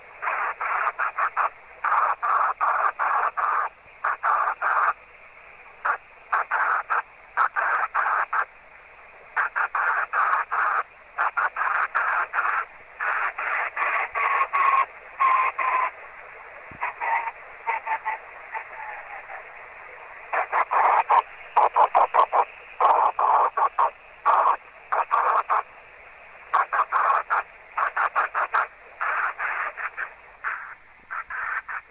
Ce jour là fort Rain Scatter local en écoutant les balises de la région.
Au milieu de l'enregistrement je tourne le cornet dans la "vrai" direction de la balise permettent de l'entendre en direct ce qui permet de juger l'effet d'étalement important du spectre et le léger doppler du à la réflexion sur le rideau de pluie.
rainscatter_10ghz.mp3